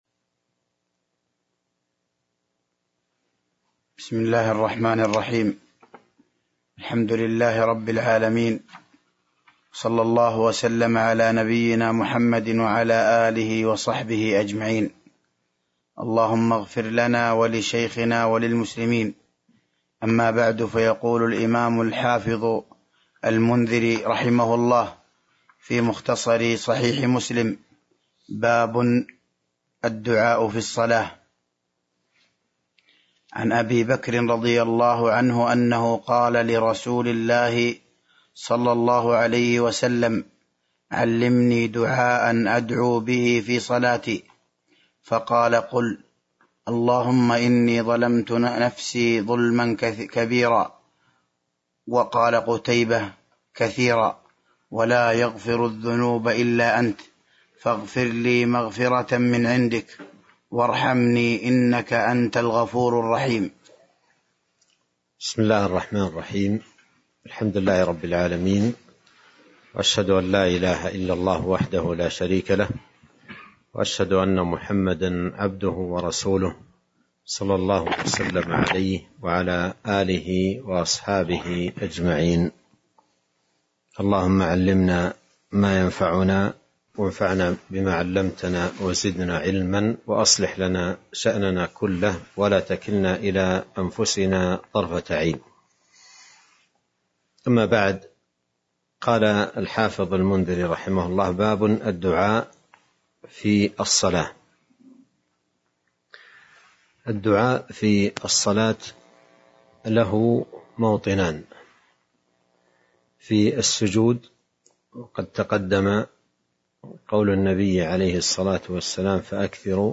تاريخ النشر ٨ جمادى الأولى ١٤٤٢ هـ المكان: المسجد النبوي الشيخ